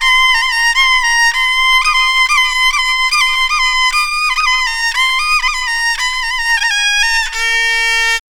KOREAVIOLN-L.wav